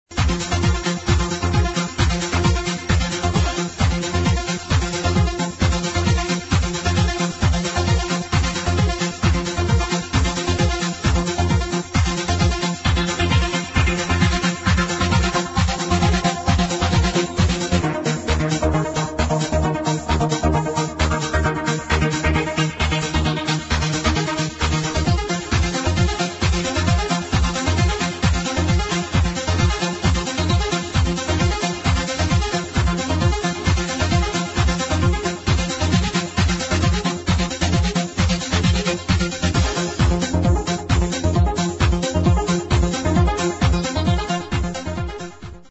[ HOUSE / EURO HOUSE ]